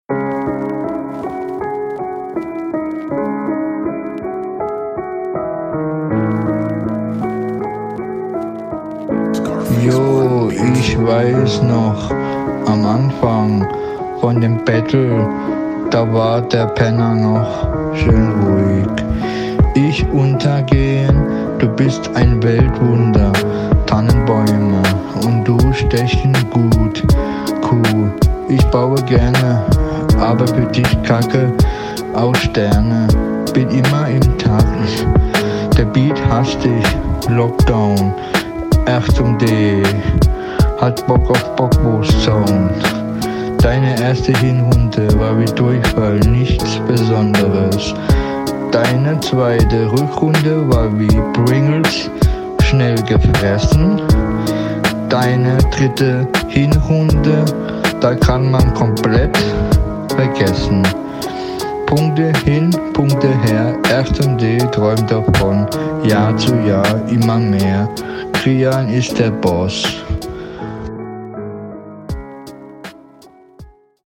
Textlich und Stimmlich kaum Verständlich. Flow nicht vorhanden.